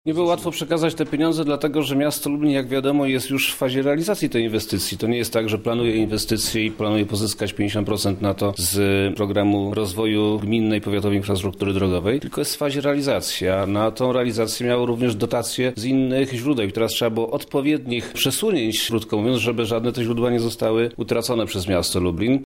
Dotacja 2 mln– tłumaczy Przemysław Czarnek, Wojewoda Lubelski.